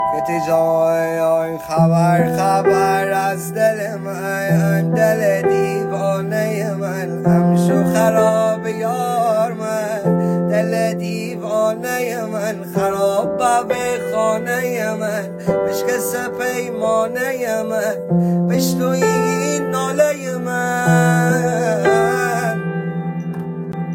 یکی از قطعات ریتمیک و احساسی است